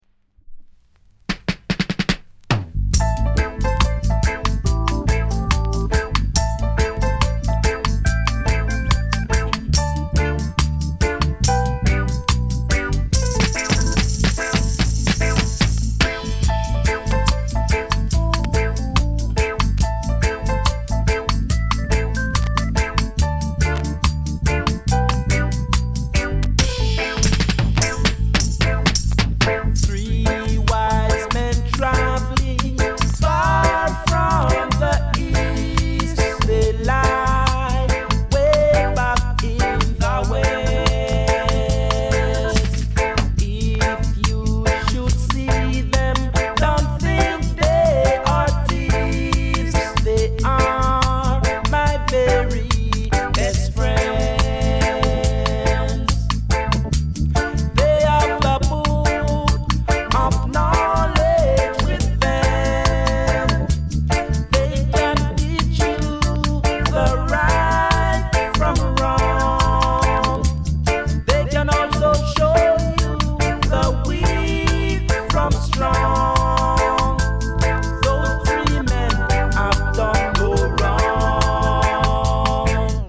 類別 雷鬼